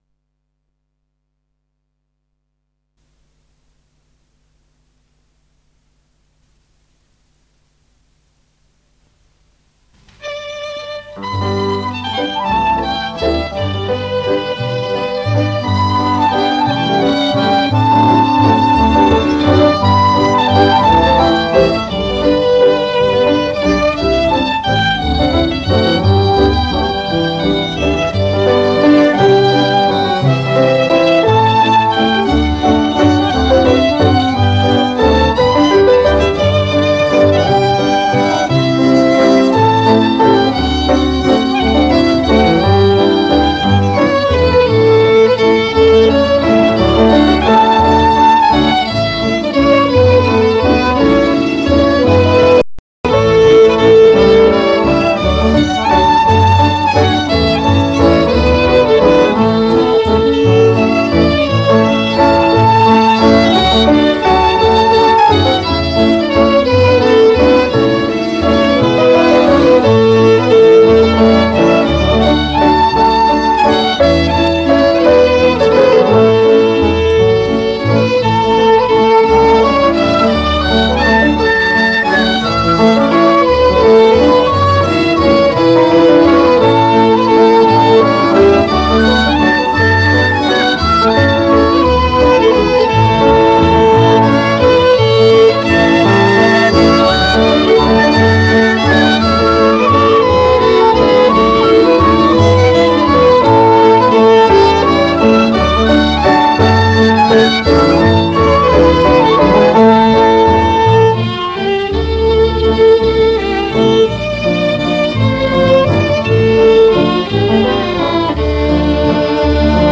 Valzer in cerchio (valzer delle rose)